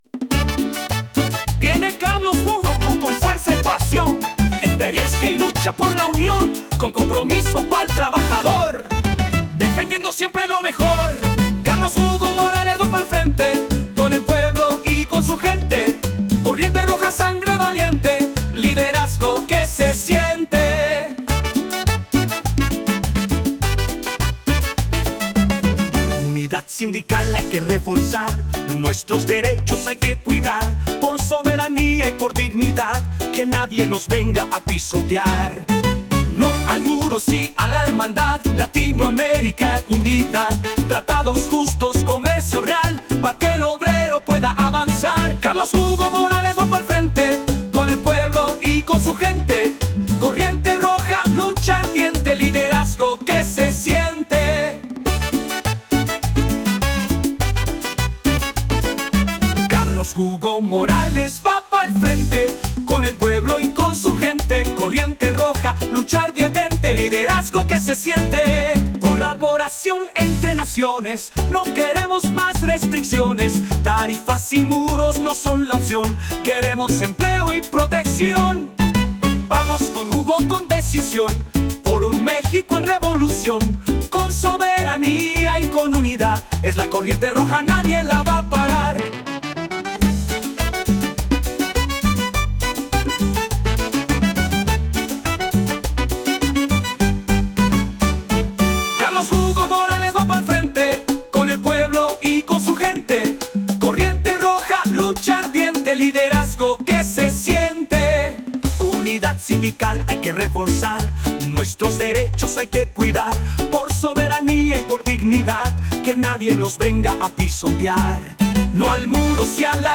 Género: Salsa